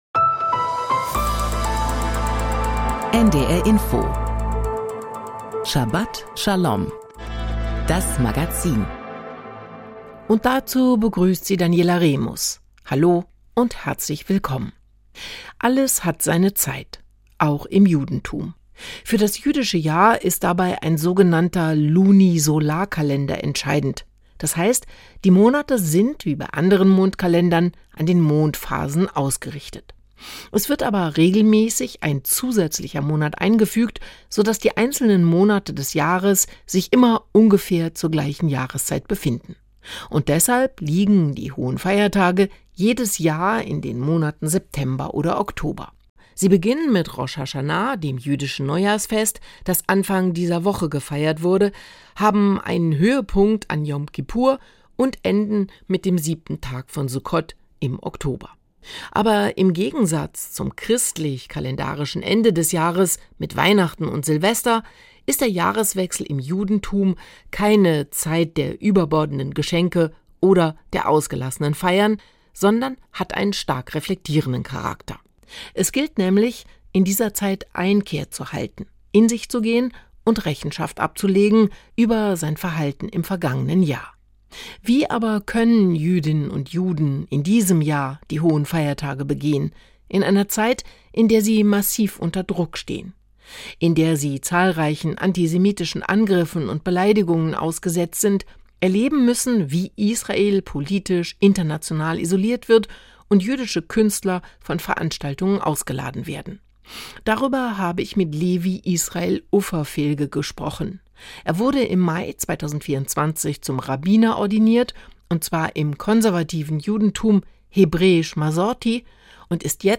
Die Hohen Feiertage in schwierigen Zeiten: Ein Gespräch
Thora-Auslegung